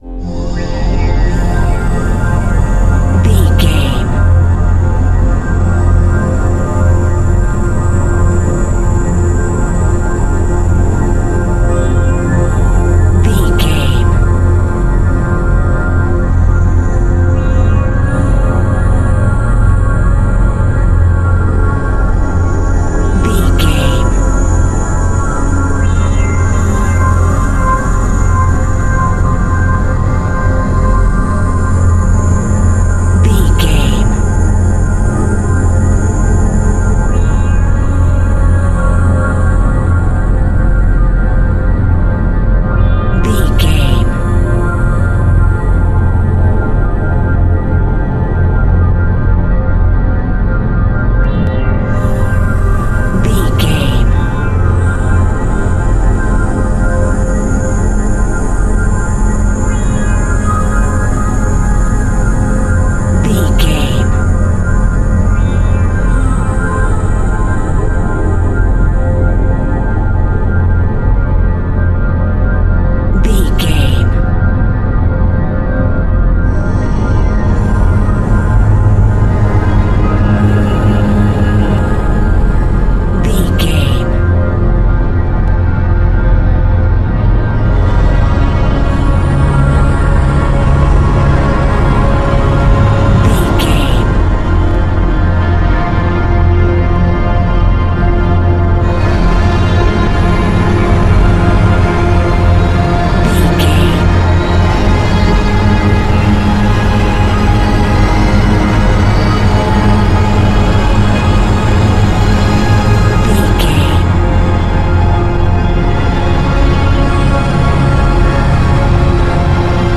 Morphing Sci Fi Music Theme.
In-crescendo
Thriller
Aeolian/Minor
tension
ominous
dark
eerie
synthesizers
Synth Pads
atmospheres